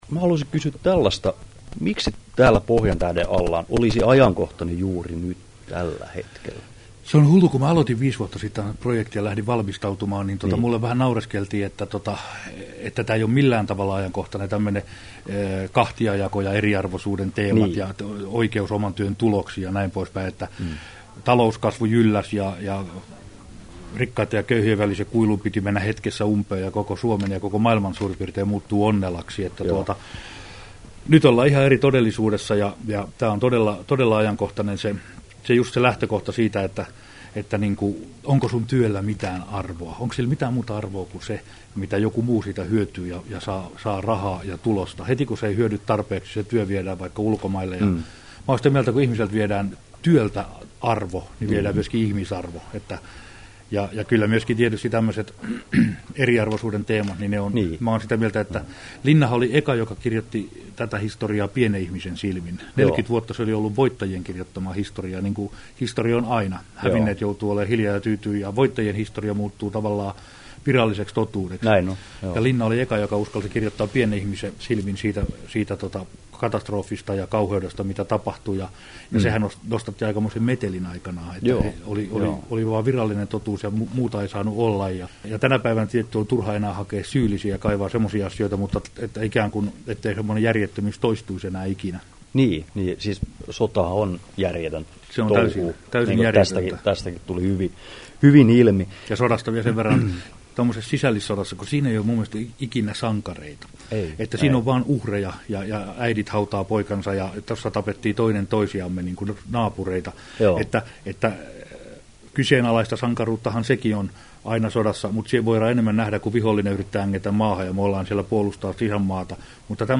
Timo Koivusalon haastattelu Kesto: 21'44" Tallennettu 23.11.2009, Turku Toimittaja